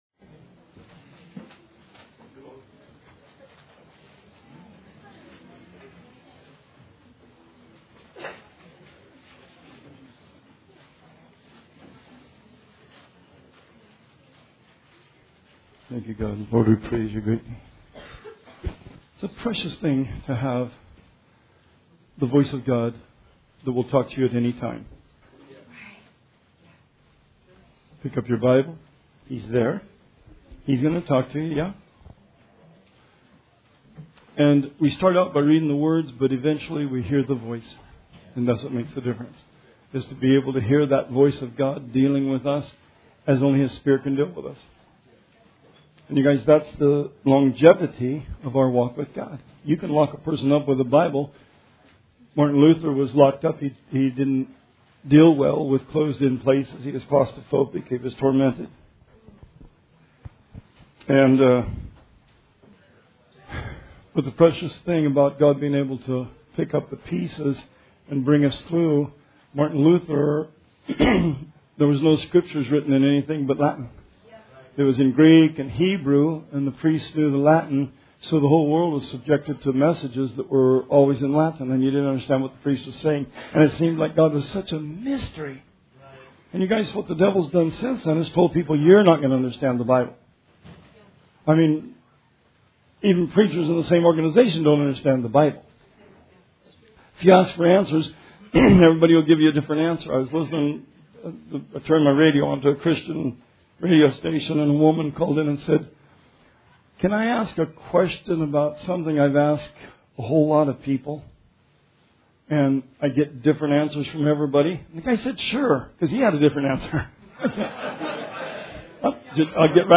Sermon 6/2/19 – RR Archives